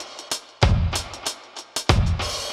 Index of /musicradar/dub-designer-samples/95bpm/Beats
DD_BeatB_95-01.wav